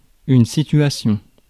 Ääntäminen
IPA: [si.tɥa.sjɔ̃]